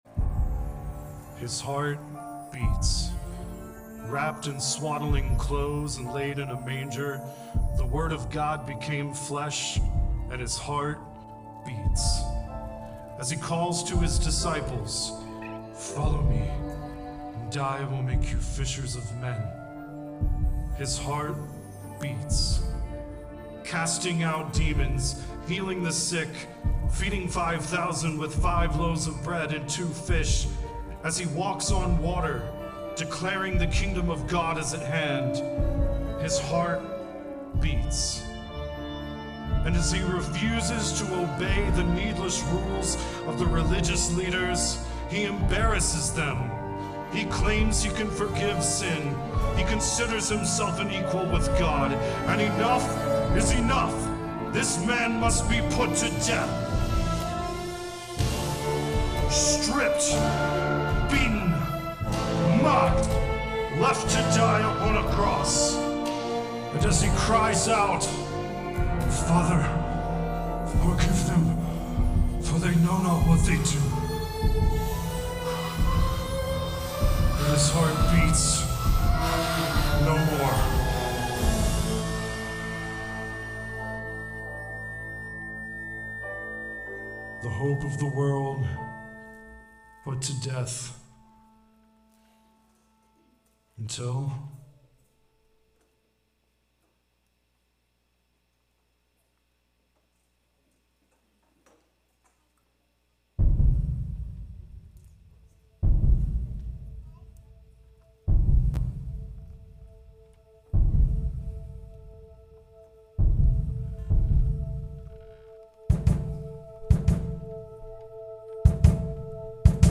4-20-25-Easter-Sunday-Service.mp3